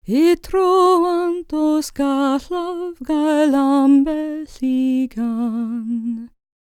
L CELTIC A12.wav